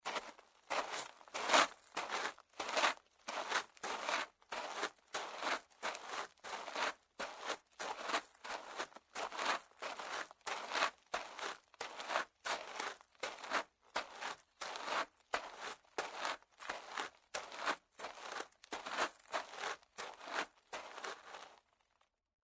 SFX雪地上的脚步声6音效下载